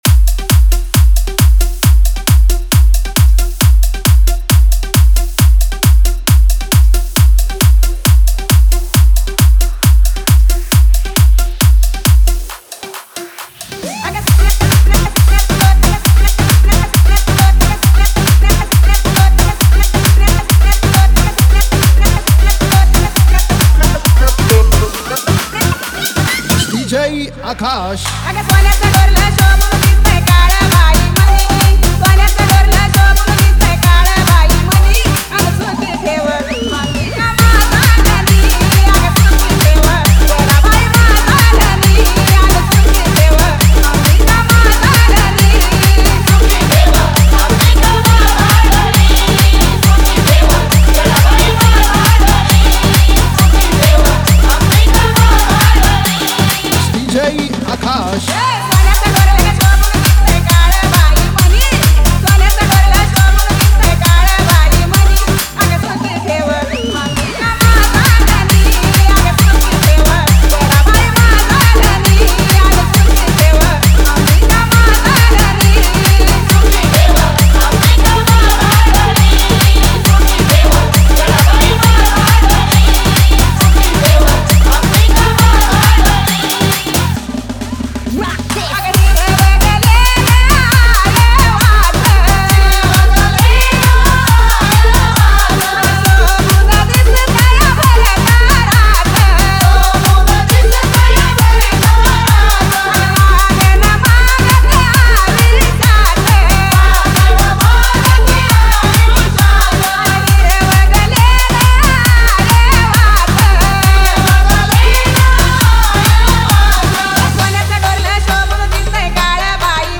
Navratri Dj Remix Song Play Pause Vol + Vol